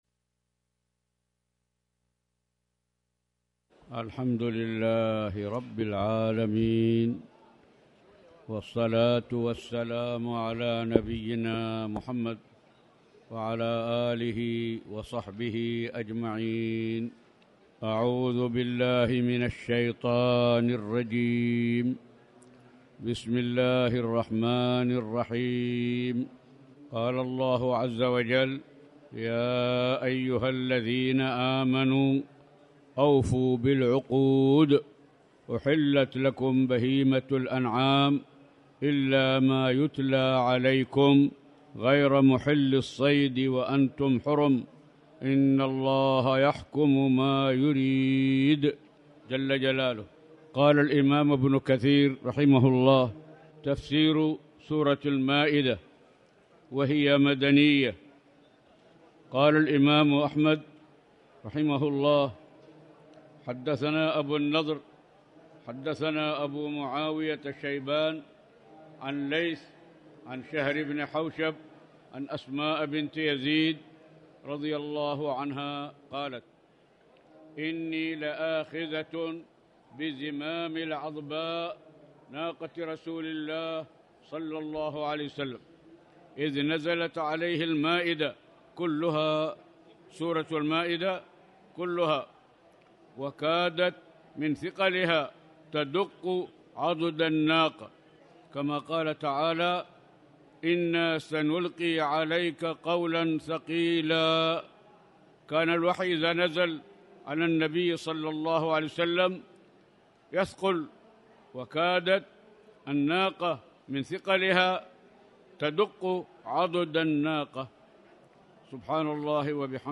تاريخ النشر ١ صفر ١٤٣٩ هـ المكان: المسجد الحرام الشيخ